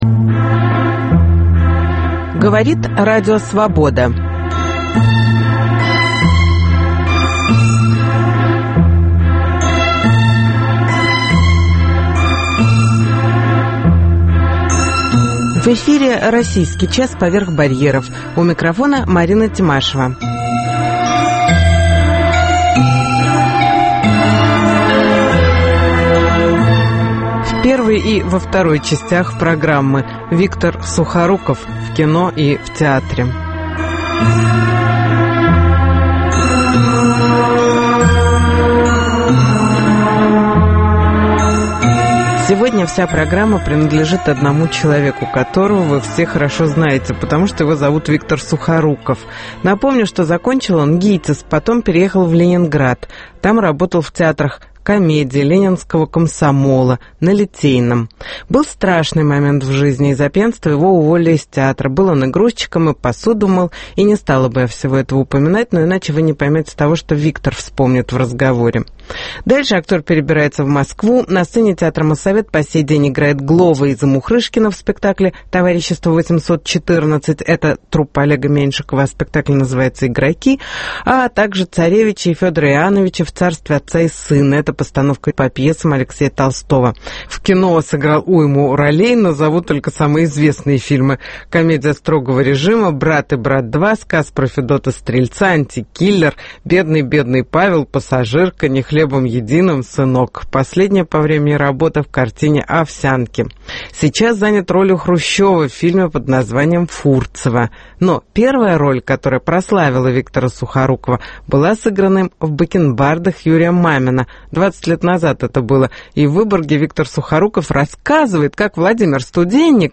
Интервью с Виктором Сухоруковым (часть первая)